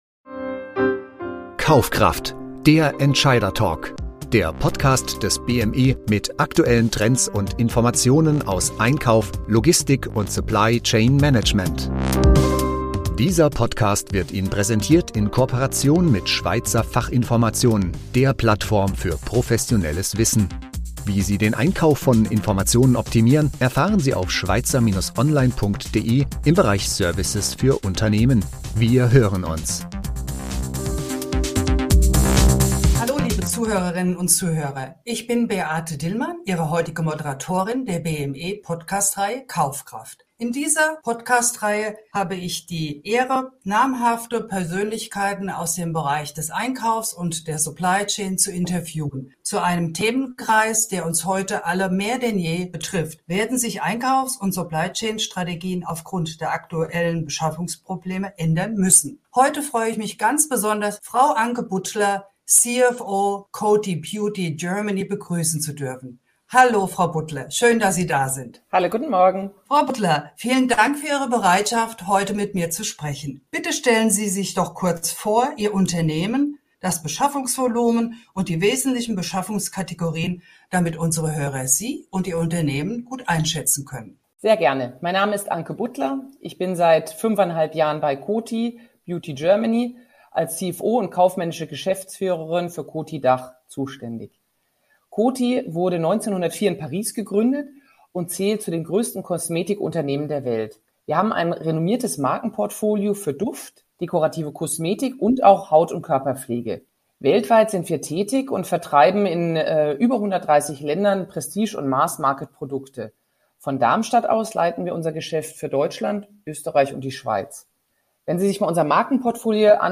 Beschreibung vor 3 Jahren *dieses Interview wurde bereits im Mai 2022 aufgezeichnet COTY als größtes Kosmetikunternehmen der Welt hat verschiedene Strategien entwickelt, um auf die Herausforderungen der Pandemie und damit Liefer- und Logistikketten-Herausforderungen zu reagieren.